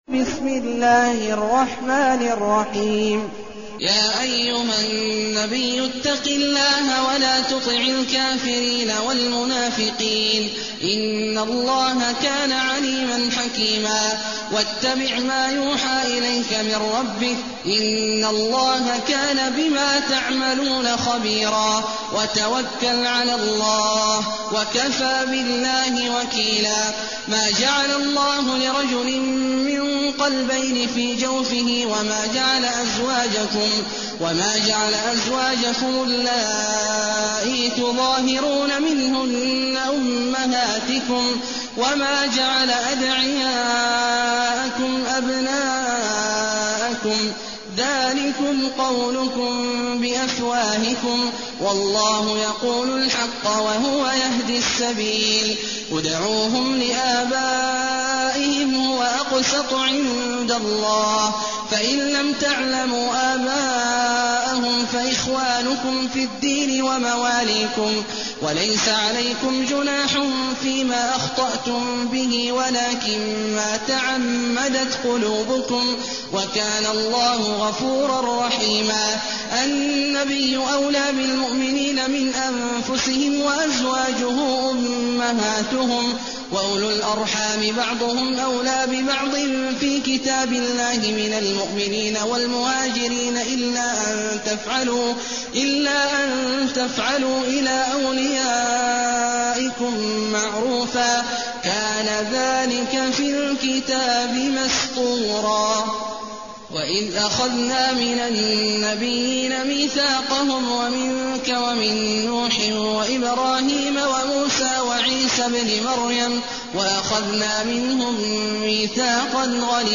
المكان: المسجد النبوي الشيخ: فضيلة الشيخ عبدالله الجهني فضيلة الشيخ عبدالله الجهني الأحزاب